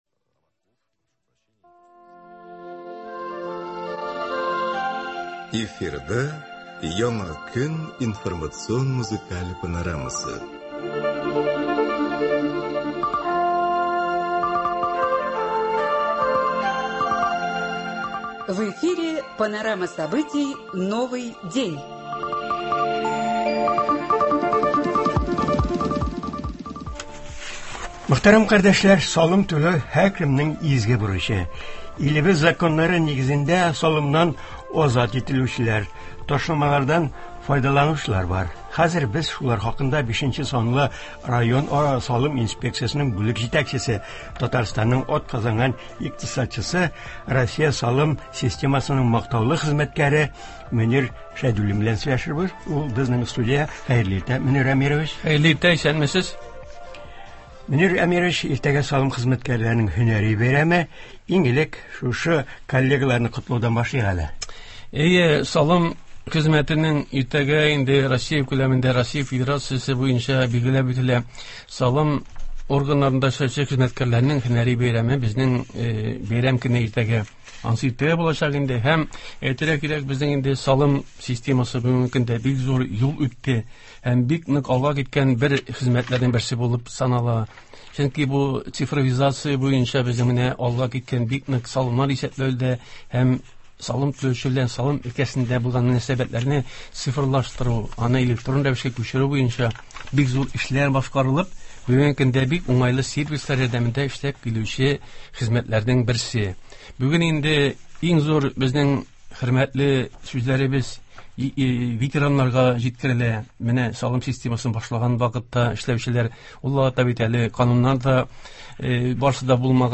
Туры эфир (20.11.23)